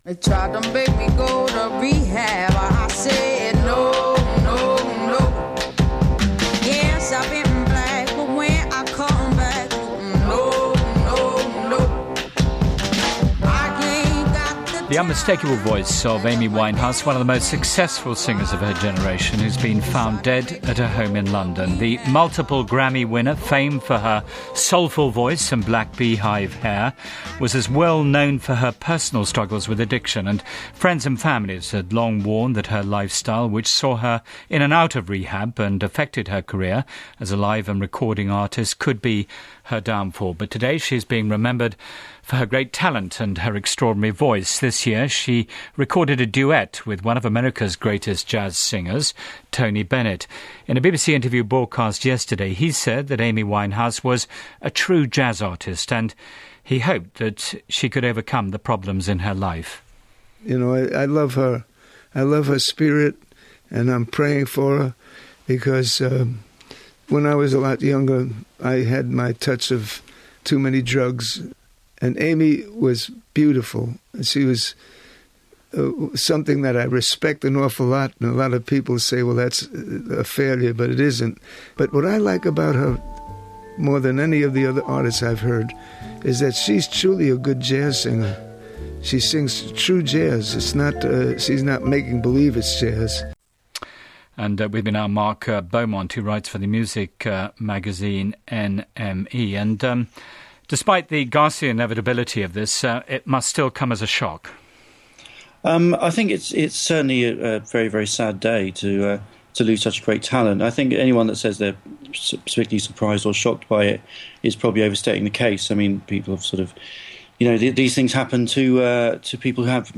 July 23, 2011 – BBC World Service – Newshour – Report on Death of Amy Winehouse – BBC